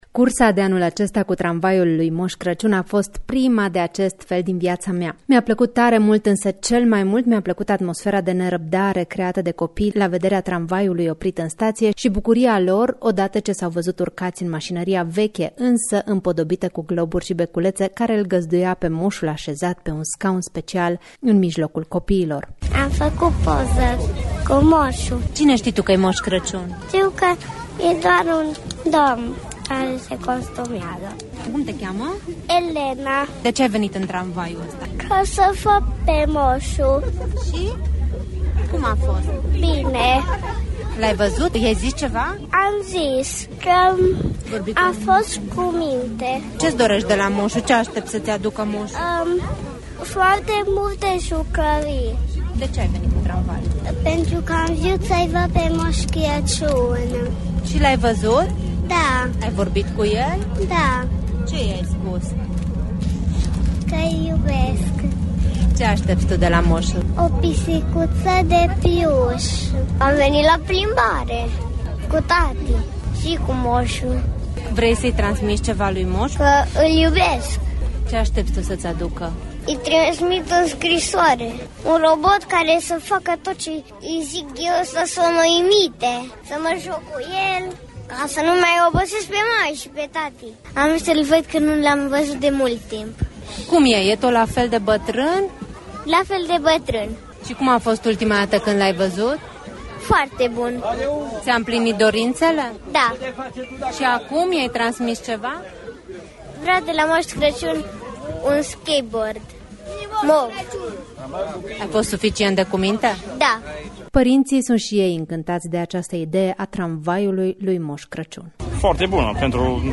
reprtaj-tramvai-mos.mp3